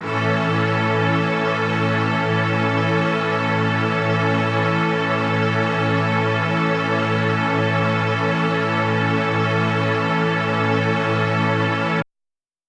SO_KTron-Ensemble-Amaj.wav